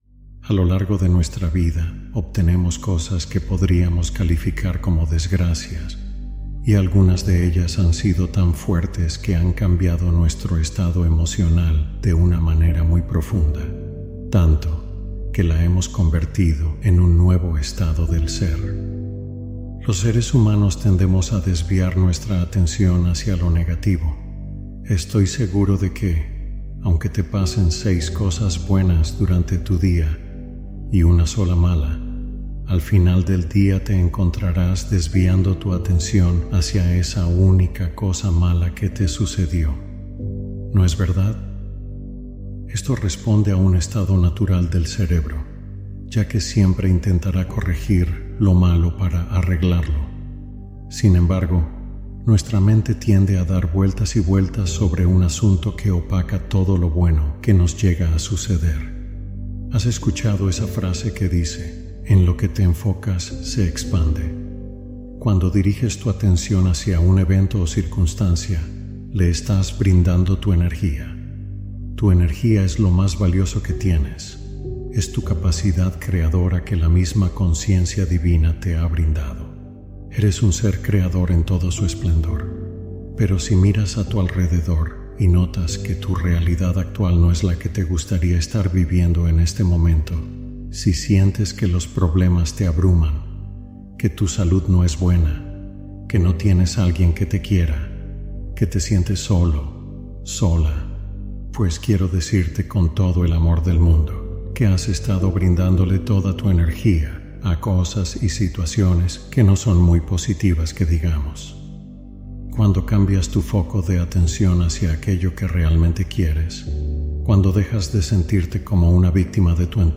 ESCUCHA UNA NOCHE Y LAS COSAS BUENAS COMENZARÁN A SUCEDER | Hipnosis y Afirmaciones